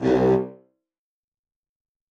LEMONHEAD BRASS.wav